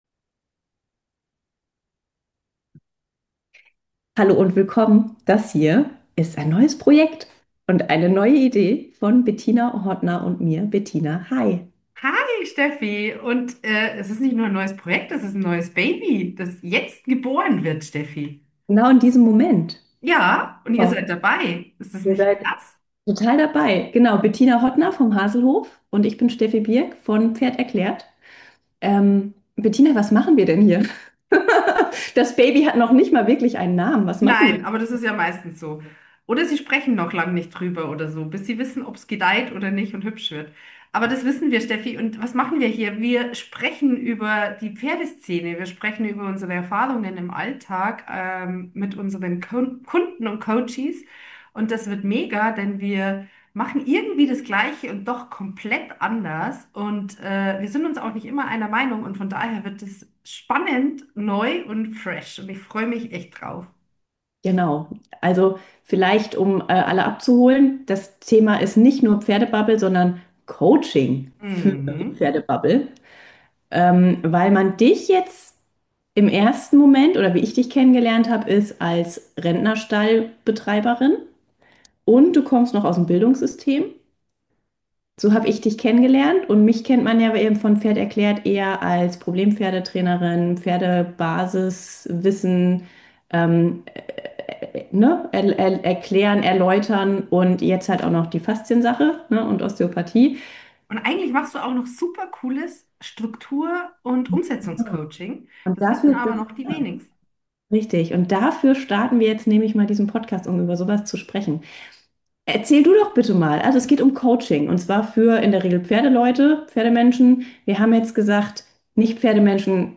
Zwei Frauen.